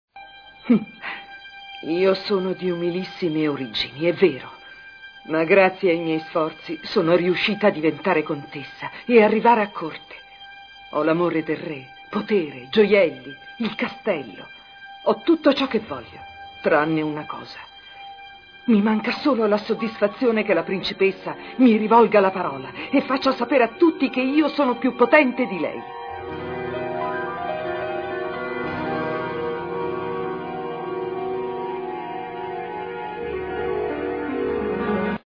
dal cartone animato "Lady Oscar", in cui doppia la Contessa Dubarry.